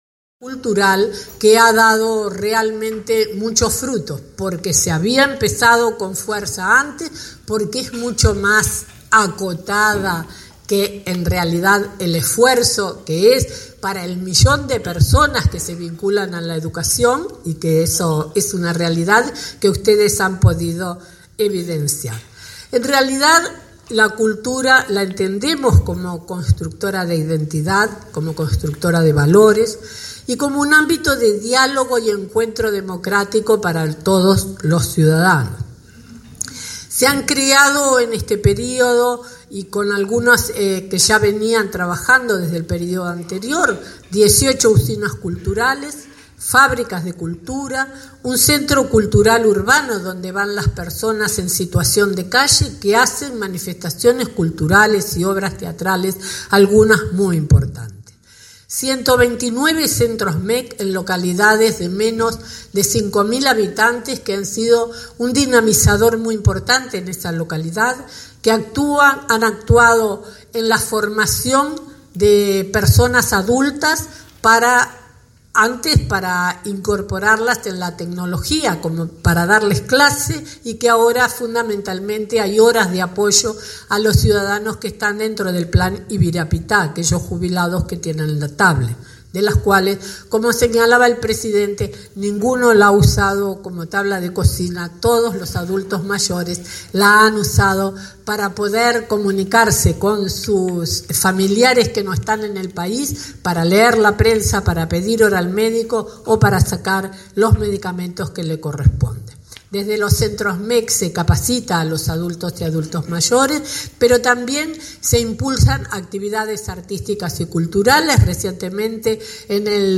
La ministra María Julia Muñoz hizo un repaso de las políticas culturales realizadas en los últimos años, en un desayuno de trabajo de Somos Uruguay.